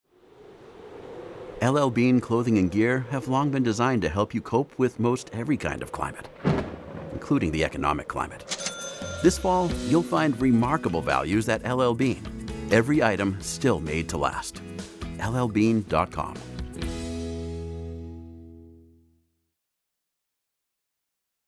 Mature Adult, Adult
The friendly, accessible, trustworthy, authoritative, “coffee-laced-with-caramel” voice of the nerdy, fun dad next door.
standard us
documentary